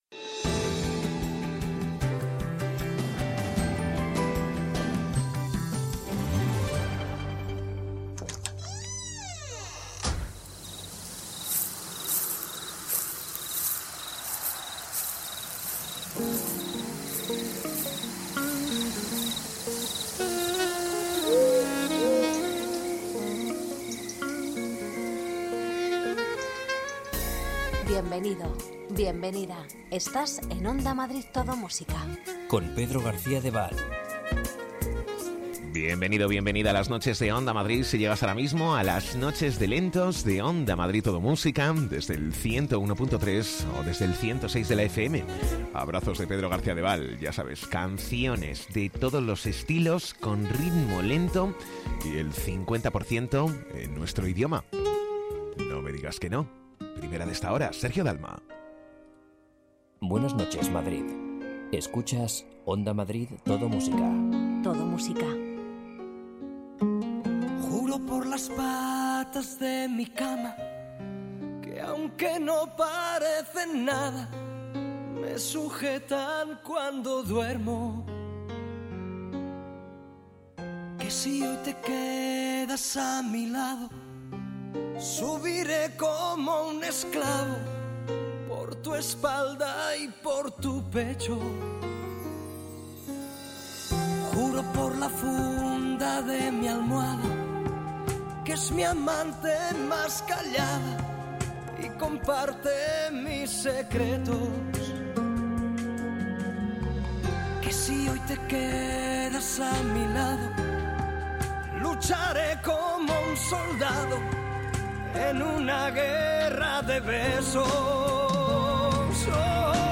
Ritmo tranquilo, sosegado, sin prisas... Las canciones que formaron parte de la banda sonora de tu vida tanto nacionales como internacionales las rescatamos del pasado durante la madrugada. La magia de la radio de noche presente desde Onda Madrid Todo Música.